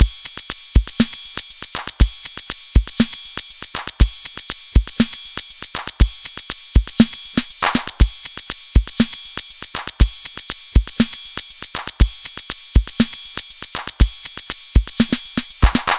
FV Add Drums 120
描述：with Addictive Drums
标签： 120 bpm Electronic Loops Drum Loops 1.35 MB wav Key : Unknown
声道立体声